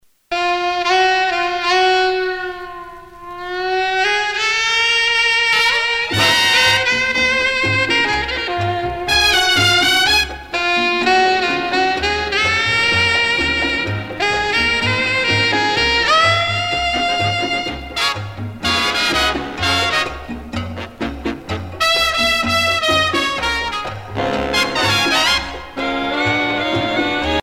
Usage d'après l'analyste gestuel : danse
Pièce musicale éditée